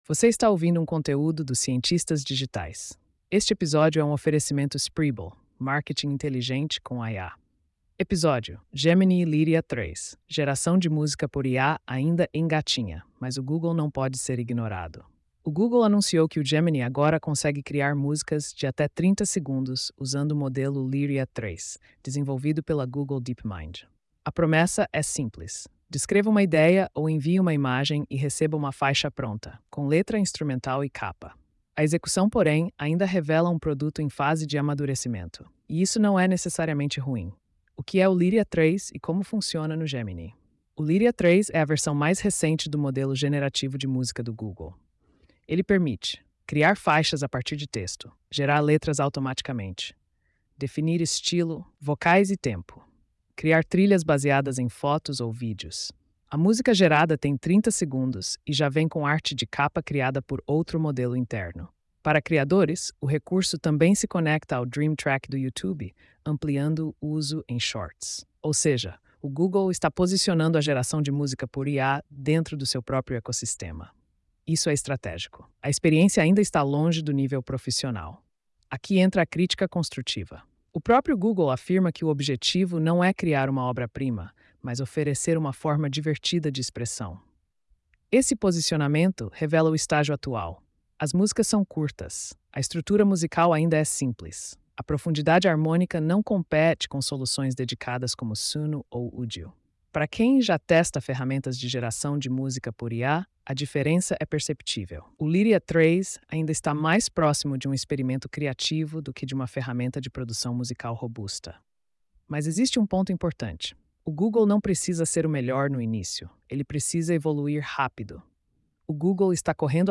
post-4671-tts.mp3